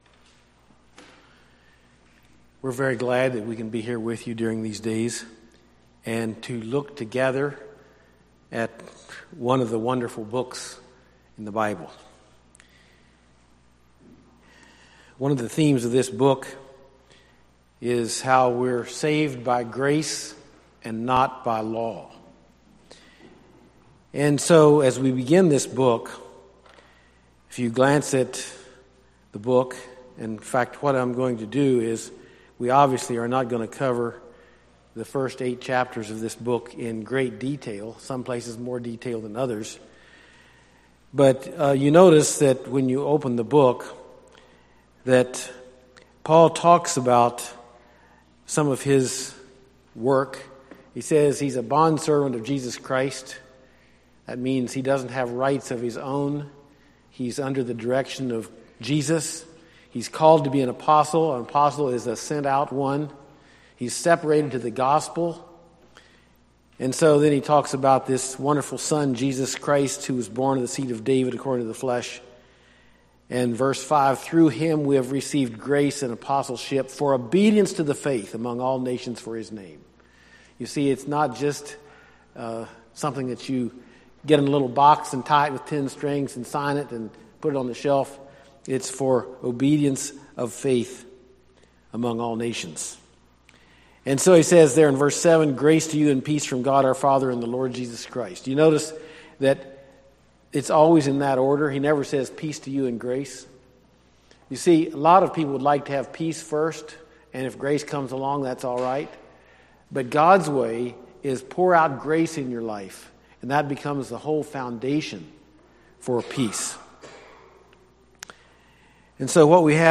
Series on Romans Service Type: Thursday Evening